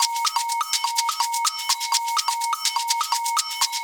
Live Percussion A 12.wav